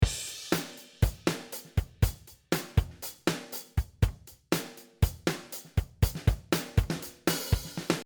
使用している音源は、Superior Drummer 2の標準キットをそのままパラアウトで使っています。
ビンテージっぽい音色
ドラム全体
高域をバッサリとカットし、中低域〜中域を強調する事で、ビンテージっぽい音にしてみました。
正直、他のパートも同じような処理をしないとちょっとバスドラが浮いてしまう感じがありますね。